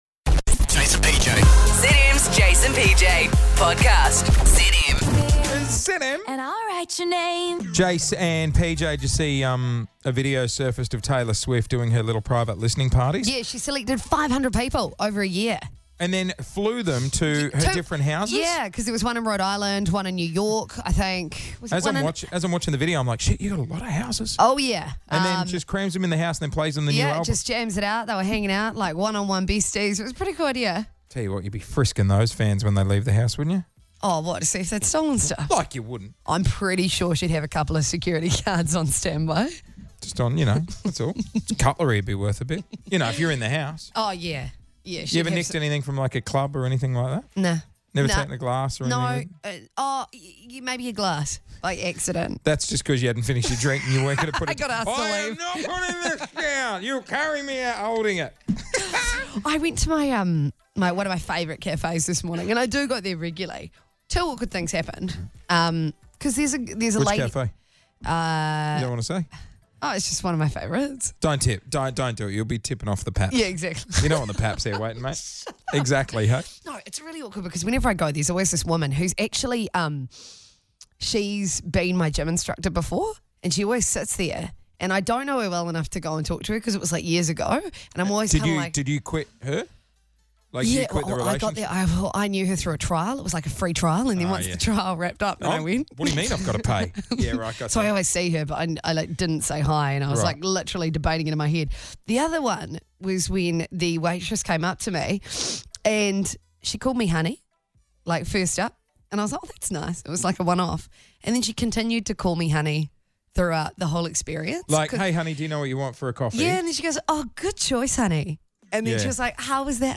Happy air freshener day New Zealand. Today is the big day and we are broadcasting live from Mobil with the sweet smell of birthday candle surrounding us.